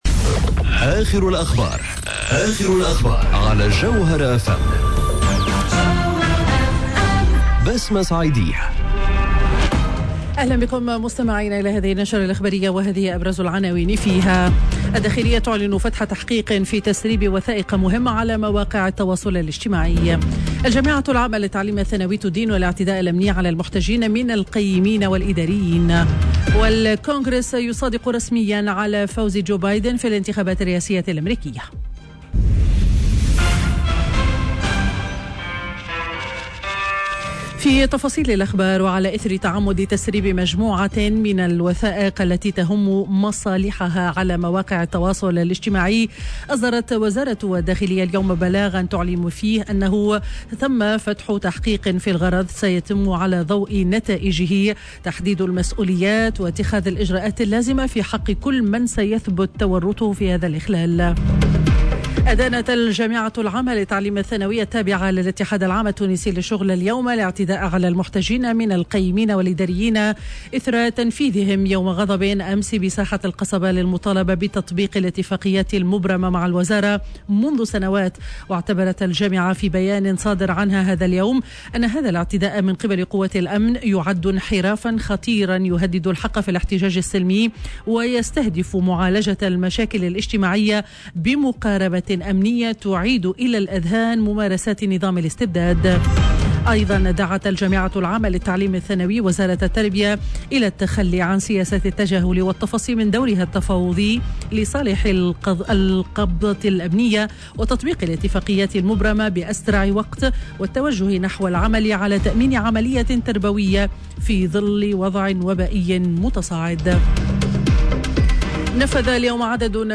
نشرة أخبار منتصف النهار ليوم الخميس 07 جانفي 2021